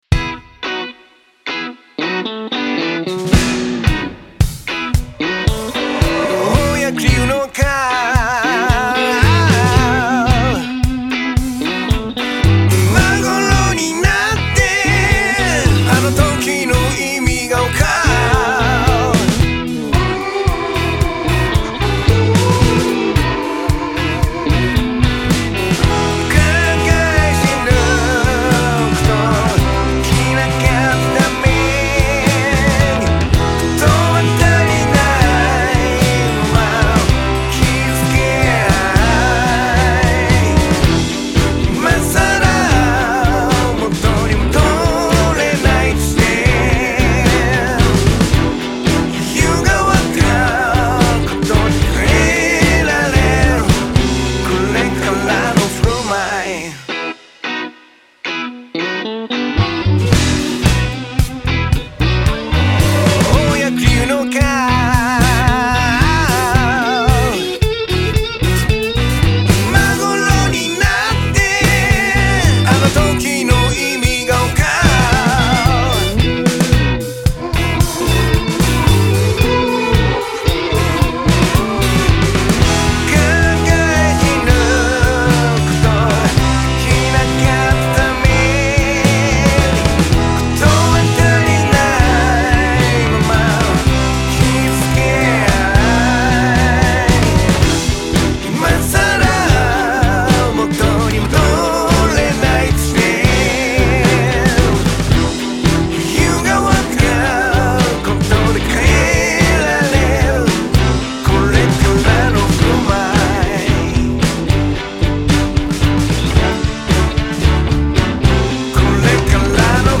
毎日1曲、新曲つくってアレンジ＆録音したものを日々アップロード中。